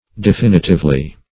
definitively - definition of definitively - synonyms, pronunciation, spelling from Free Dictionary Search Result for " definitively" : The Collaborative International Dictionary of English v.0.48: Definitively \De*fin"i*tive*ly\, adv.
definitively.mp3